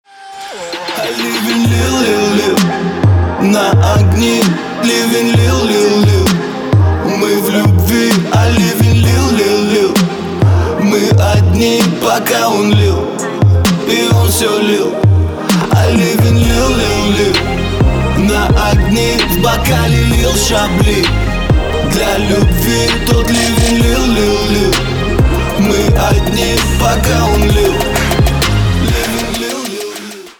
• Качество: 320, Stereo
мужской вокал
лирика
Хип-хоп
русский рэп